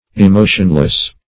emotionless \e*mo"tion*less\ adj.
emotionless.mp3